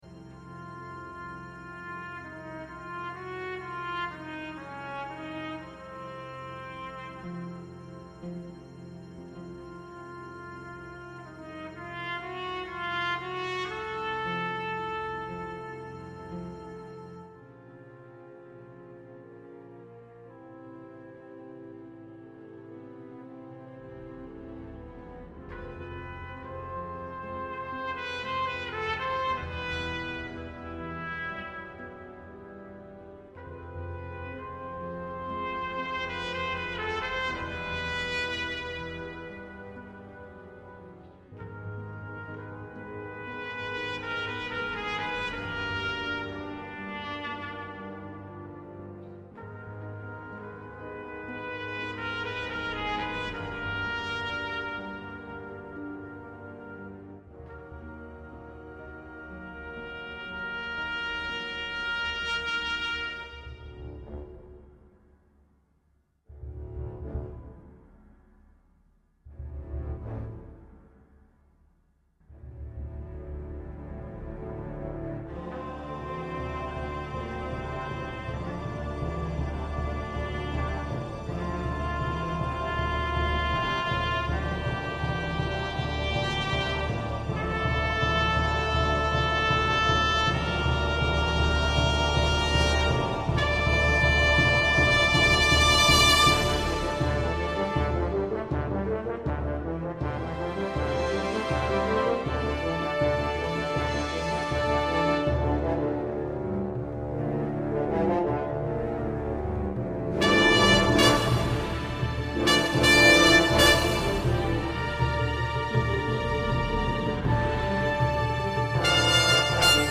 Bb Trumpet